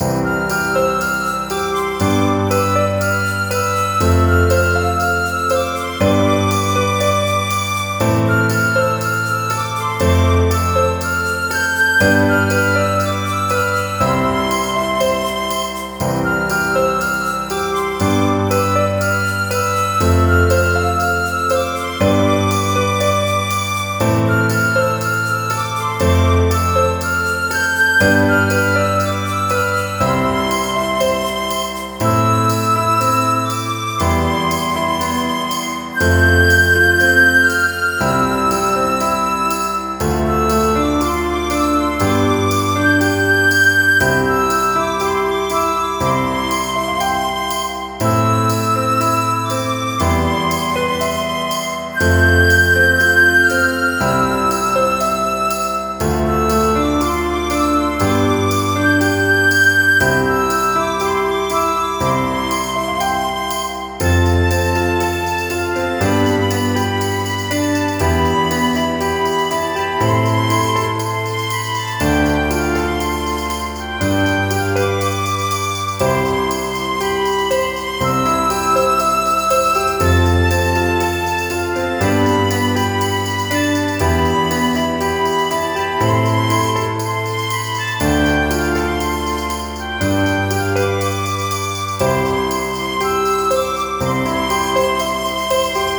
ピアノとフルートを中心に構成された、夏の情景を描く爽やかなインストゥルメンタルBGMです。
• 使用楽器：ピアノ、フルート、軽やかなパーカッション
• BPM：120
• 雰囲気：爽やか・明るい・優しい・涼しげ・ナチュラル
• ステレオ感：フルートをセンターやや前面／ピアノは左寄りに軽く配置